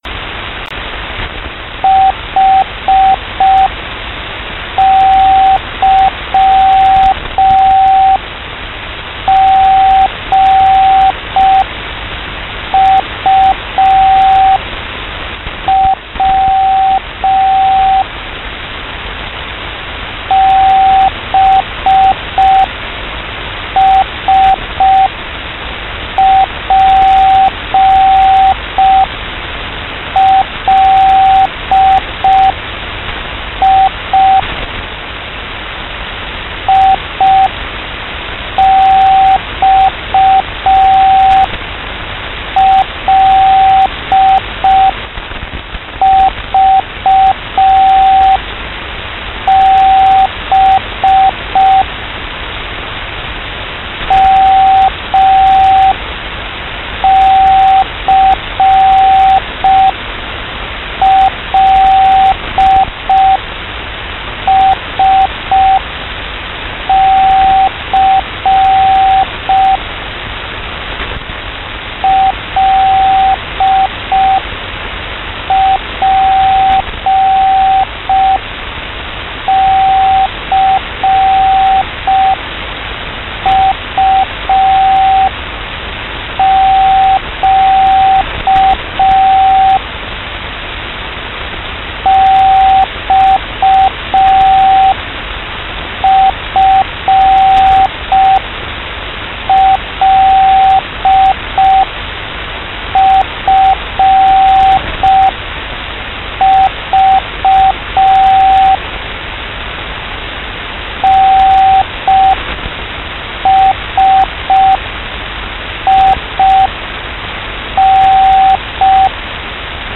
Voor jou heb ik ook een radio-opname van een Enigma bericht in morsecode (inclusief storende geluiden).
In het echt ging het echter veel sneller.
De letters worden steeds in groepjes van 5 geseind, schrijf ze ook zo op, hierdoor valt het beter op als je er per ongeluk eentje mist.
Er worden alleen letters geseind, geen cijfers.